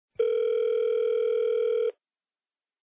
Ring.mp3